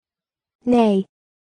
Audio file of the word "Ney"